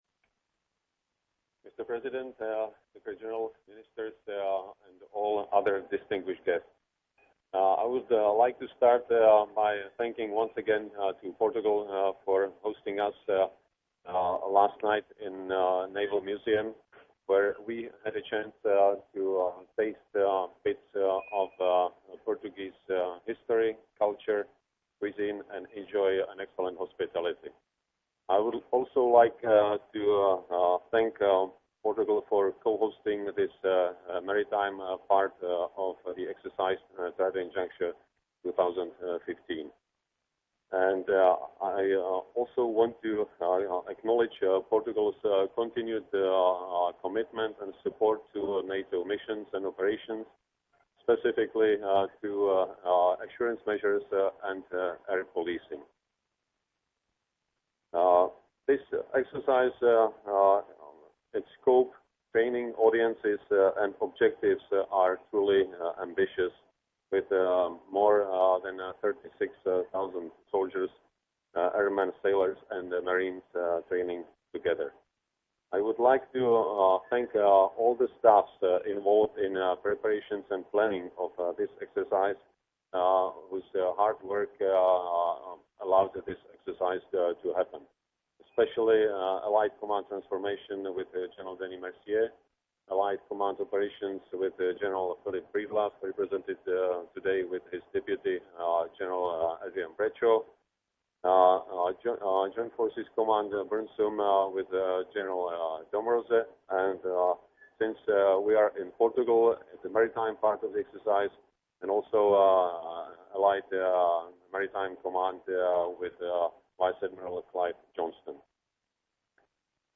Remarks by the Chairman of the NATO Military Committee, General Petr Pavel